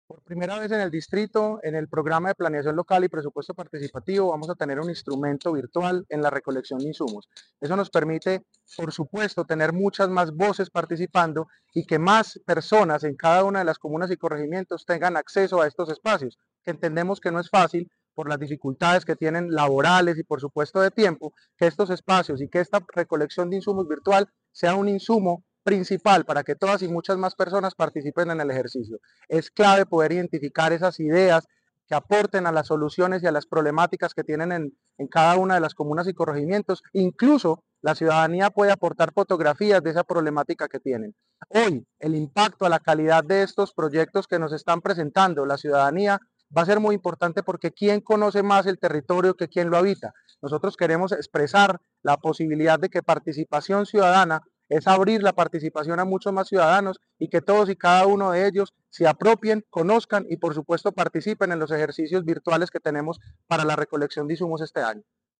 Audio Declaraciones del subsecretario de Planeación Local y Presupuesto Participativo, Alejandro Velásquez Osorio Hace un mes inició la primera fase de la Ruta Anual de Presupuesto Participativo en Medellín, con la realización de talleres presenciales en diferentes comunas y corregimientos.